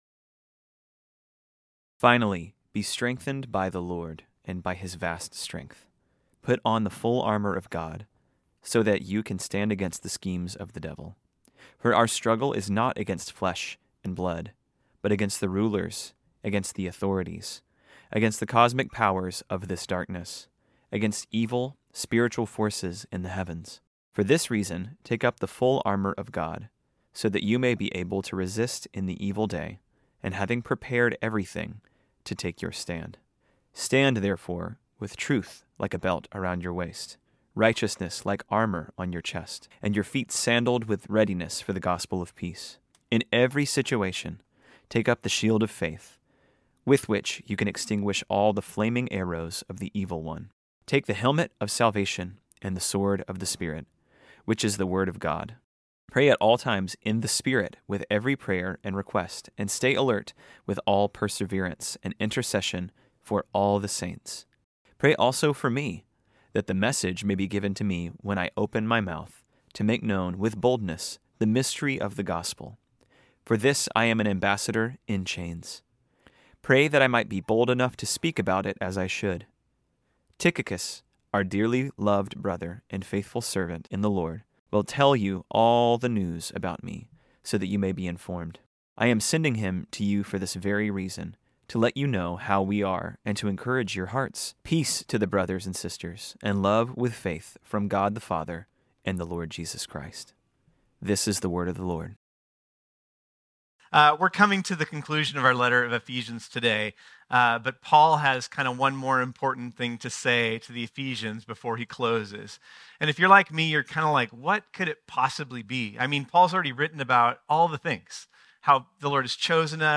This sermon was originally preached on Sunday, February 25, 2024.